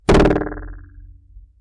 描述：来自带有2个橡皮筋和2个弹簧的接触式麦克风仪器。
标签： 模拟 接触 橡胶带 弹簧 噪声
声道立体声